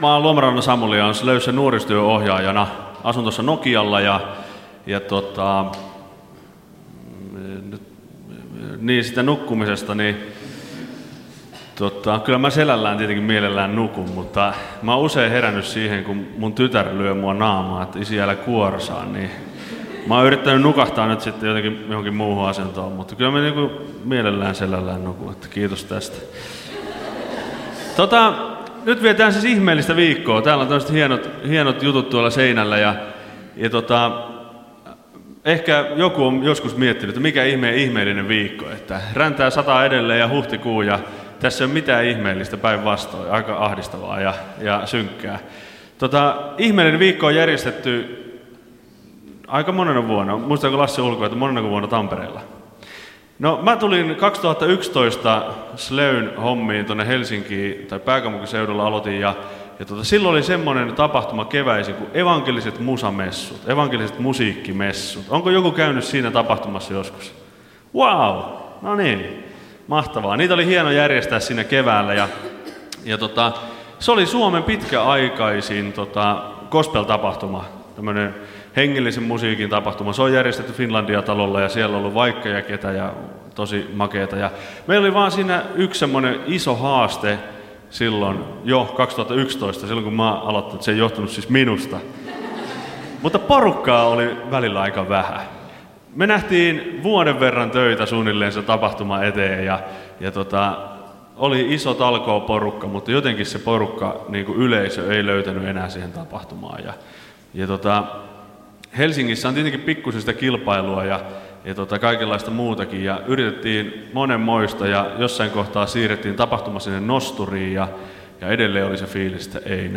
Kokoelmat: Ihmeellinen viikko Tampereella 2018